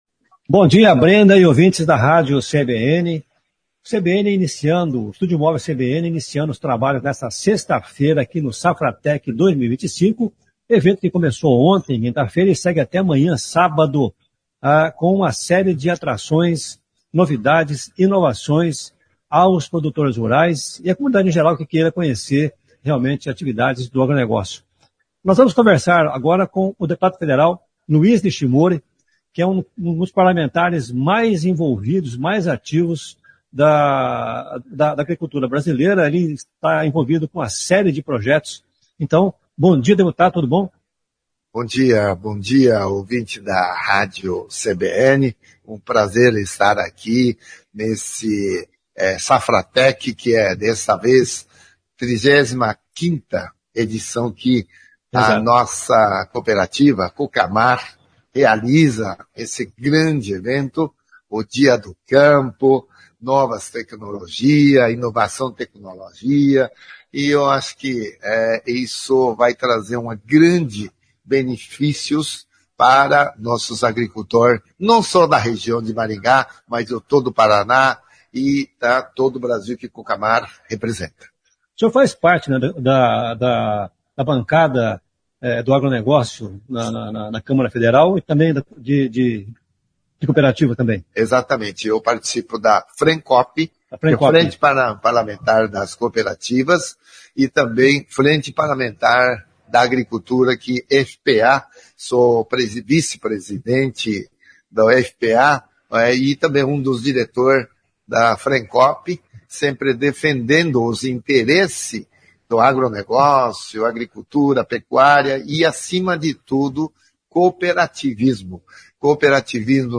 na Safratec 2025